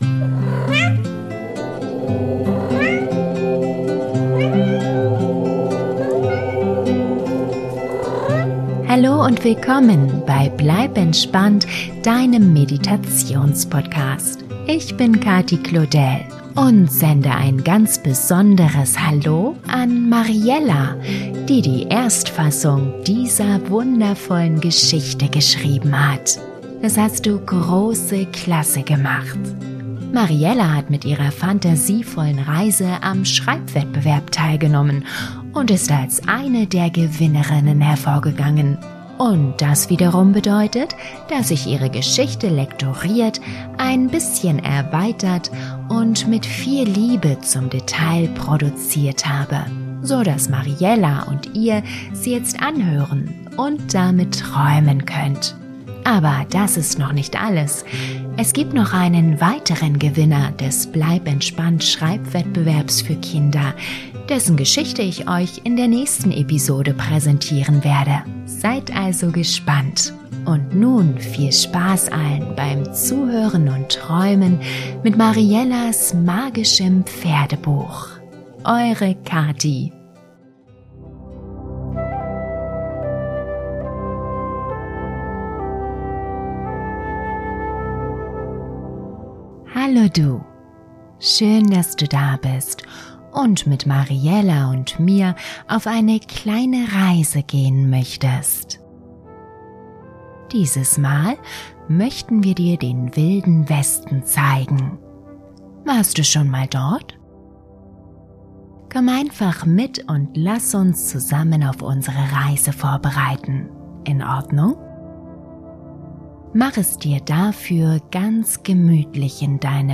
Traumreise für Kinder & Erwachsene - Das magische Pferdebuch - Pferde Geschichte ~ Bleib entspannt! Der Meditations-Podcast - magische Momente für Kinder & Eltern Podcast